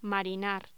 Locución: Marinar
voz